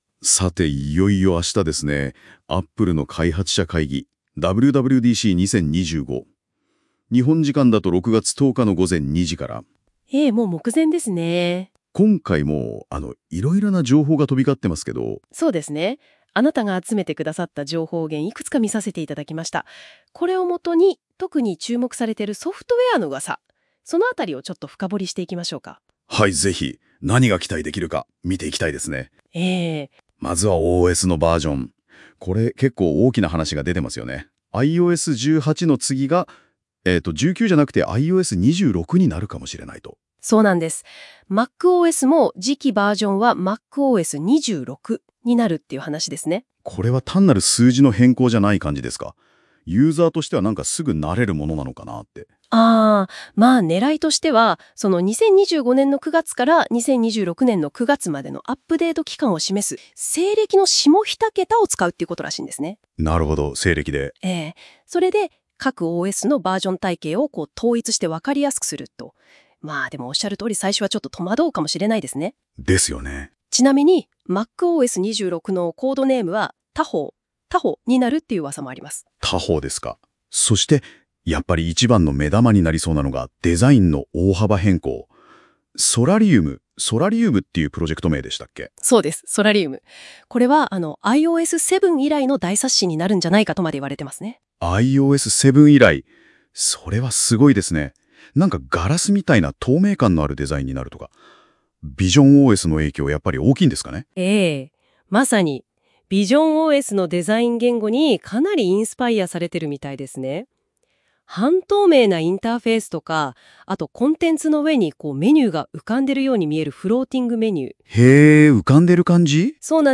（注意）上記MP3を聴いてみると、やはりパックン（男性側）の呂律が回っていないときがあるので専用のナレーションサービスに変更するかもしれません。